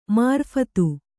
♪ mārphatu